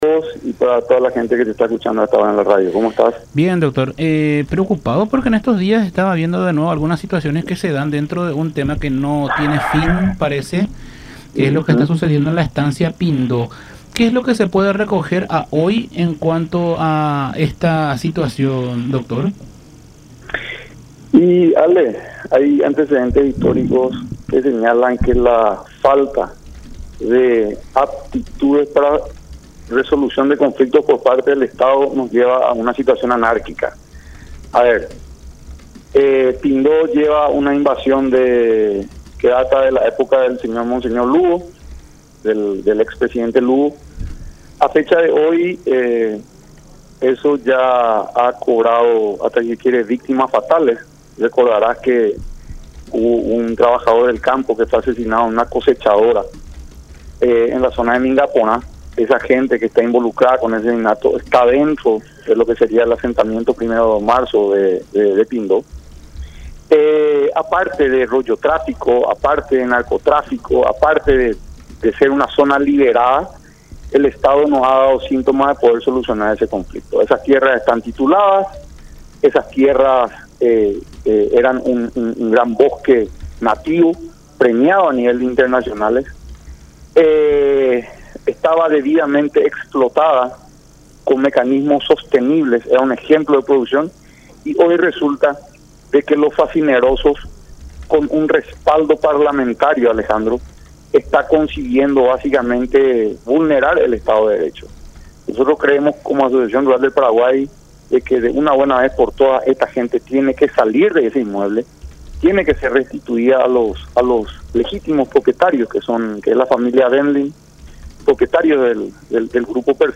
en conversación con Todas Las Voces por La Unión.